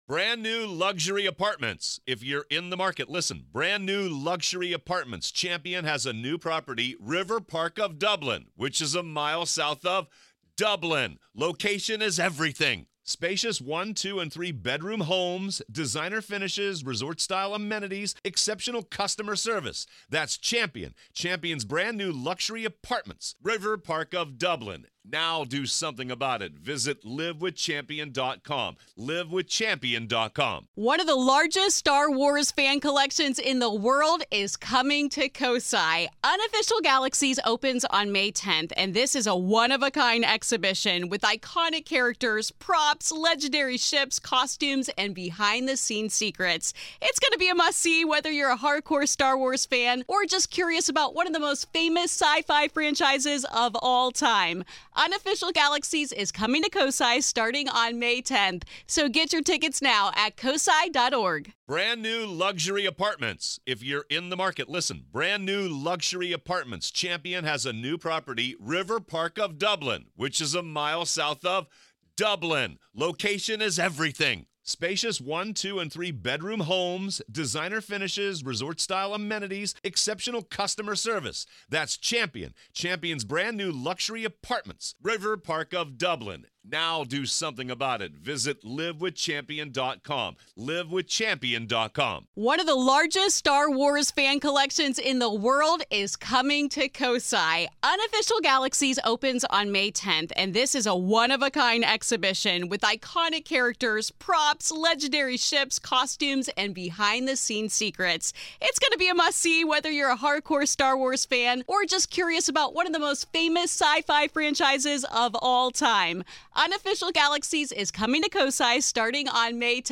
This is Part Two of our conversation.